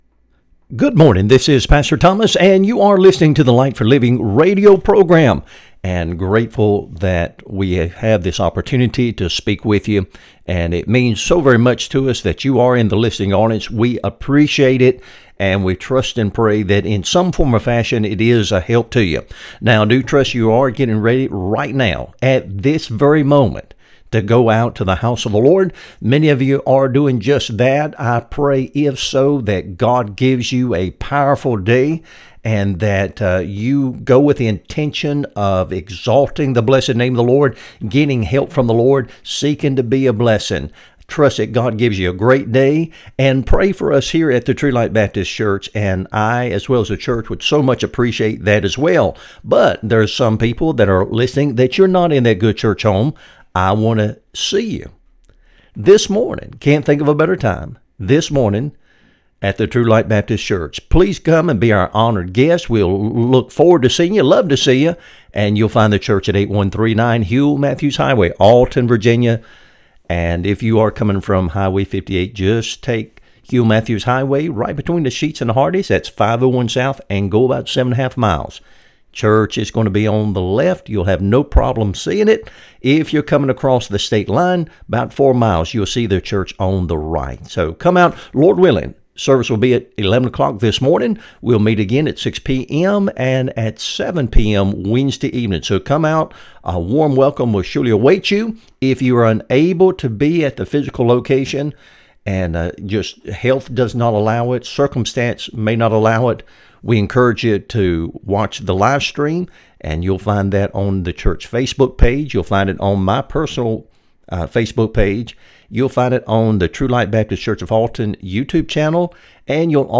Sermons | True Light Baptist Church of Alton, Virginia
Light for Living Radio Broadcast